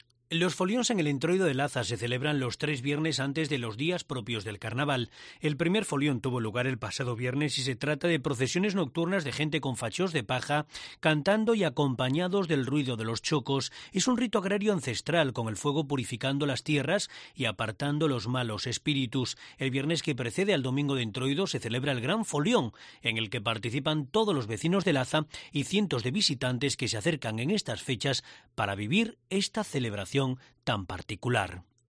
Vecinos de Laza participando en el "folión" del Entroido